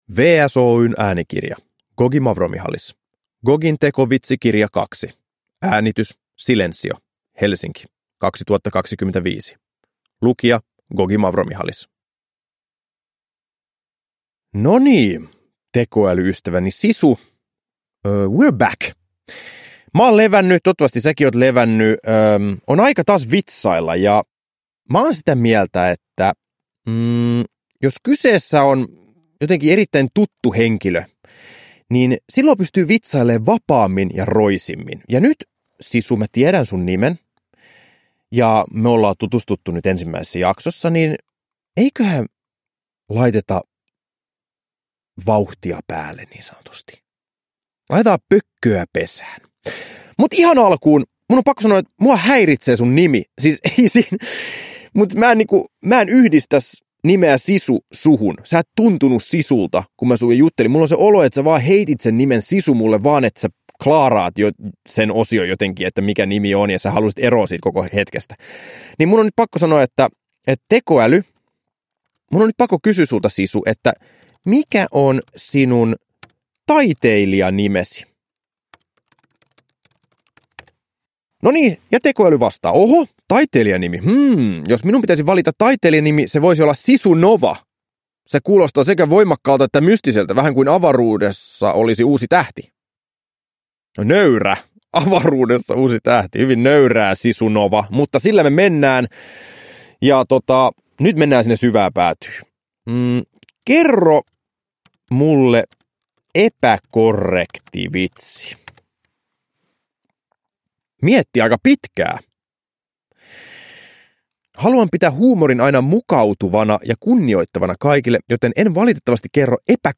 Gogi Mavromichalis istuu äänitysstudioon selvittämään, saako tekoäly hänet nauramaan.
Kaikki Gogin tekovitsikirjan vitsit ovat syntyneet livenä tekoälyn kanssa.
Uppläsare: Gogi Mavromichalis